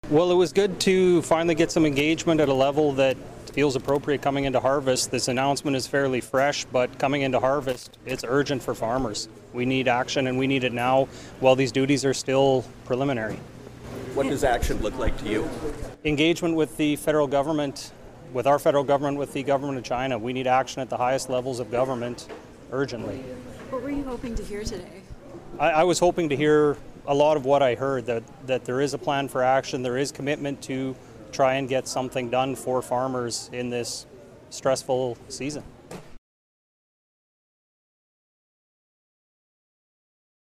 He spoke to reporters following the meeting